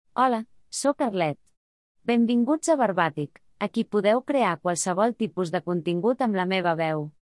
Arlet — Female Catalan AI voice
Arlet is a female AI voice for Catalan.
Voice sample
Listen to Arlet's female Catalan voice.
Female
Arlet delivers clear pronunciation with authentic Catalan intonation, making your content sound professionally produced.